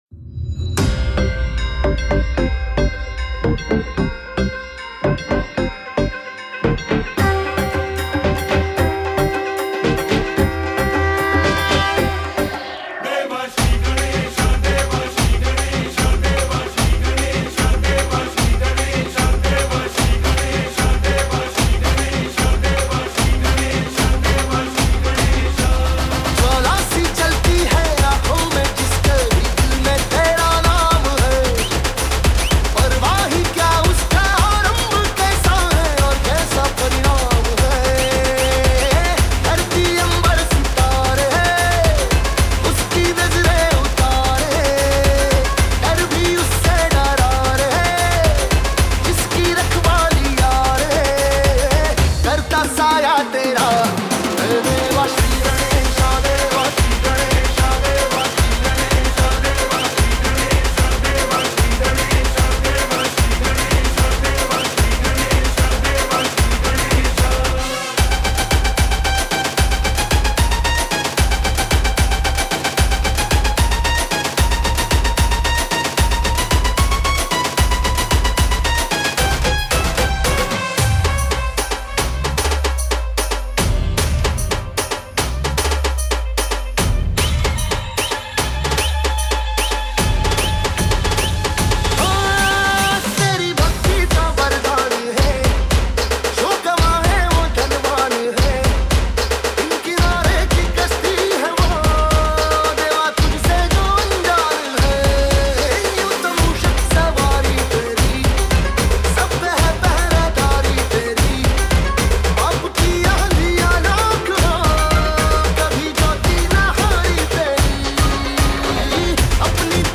DJ Remix Mp3 Songs > Latest Single Dj Mixes